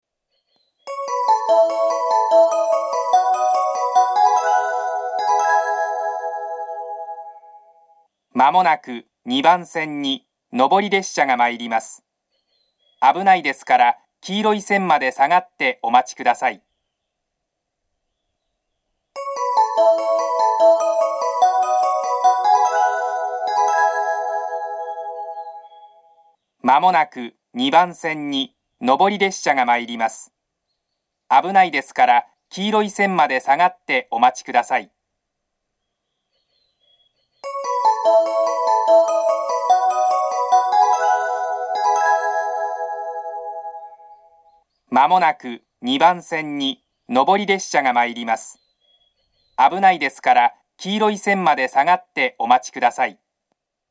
この駅の放送は東北でよく聞ける放送ではなく、カンノの放送です。接近放送は１・２番線は２回、３番線は３回流れます。
２番線上り接近放送